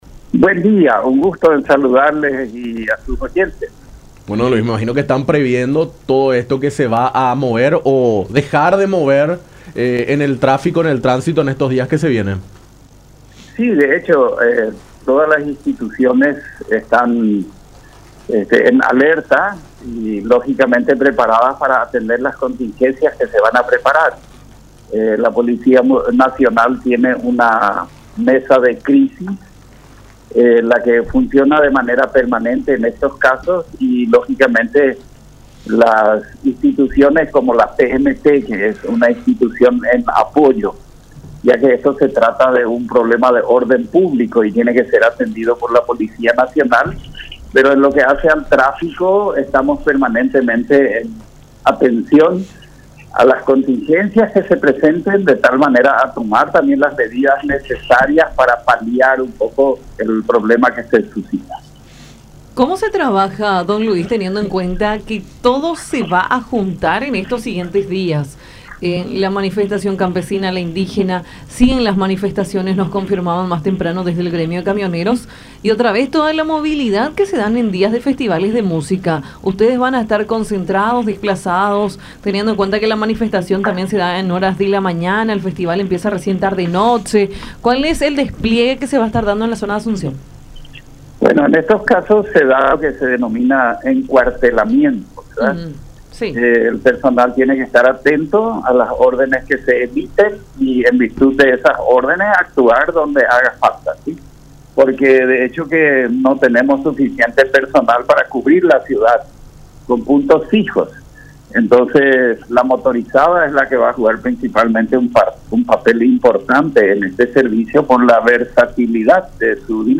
“Todas las instituciones están en alerta ante este tipo de movilizaciones y más nosotros, que somos una institución en apoyo tomando en cuenta que se trata de un problema de orden público. De todos modos, estamos atentos ante las posibles contingencias que se puedan presentar”, dijo Luis Christ Jacobs, titular de la PMT de Asunción, en conversación con Nuestra Mañana por La Unión, afirmando que una de las labores principales se presentará en zona de la Plaza Uruguaya.